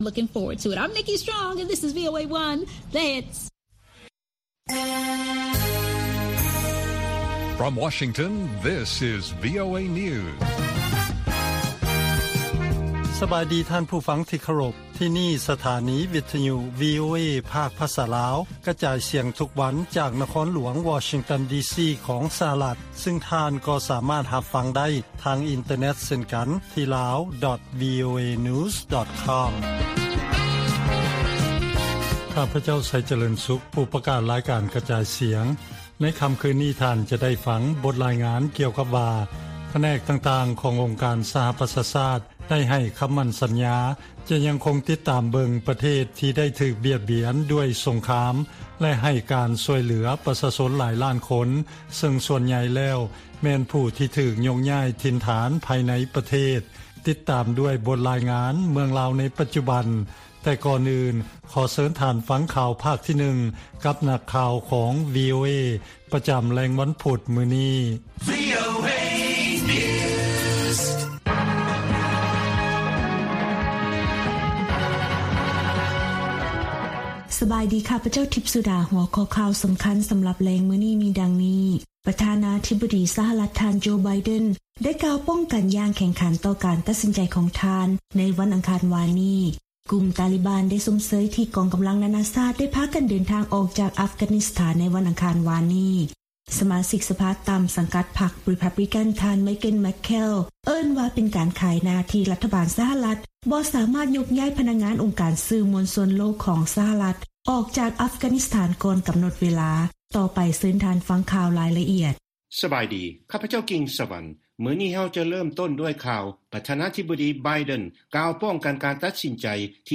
ລາຍການກະຈາຍສຽງຂອງວີໂອເອ ລາວ: ປ. ໄບເດັນ ກ່າວປ້ອງກັນຢ່າງແຂງຂັນ ໃນການສິ້ນສຸດສົງຄາມເກືອບສອງທົດສະວັດໃນອັຟການິສຖານ
ວີໂອເອພາກພາສາລາວ ກະຈາຍສຽງທຸກໆວັນ.